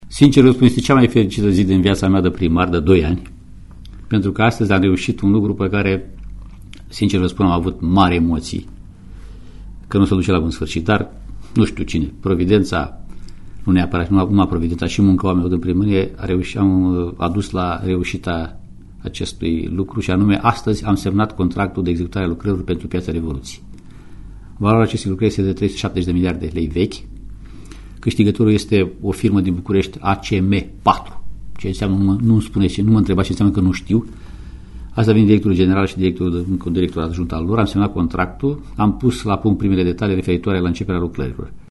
Primarul Alexandru Stoica precizează că aceasta este cea mai mare realizare din mandatul de până acum: